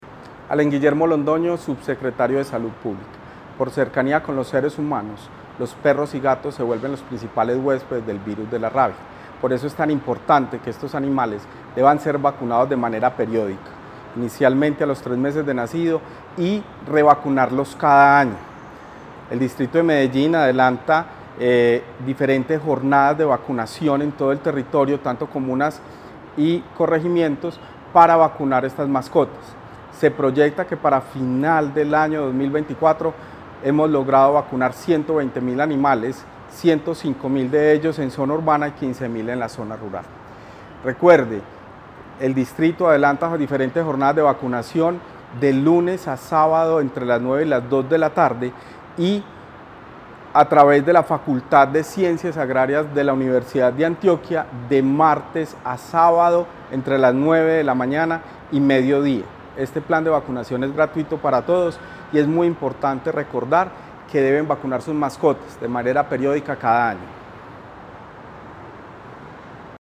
Palabras de Allen Guillermo Londoño Parra, subsecretario de Salud Pública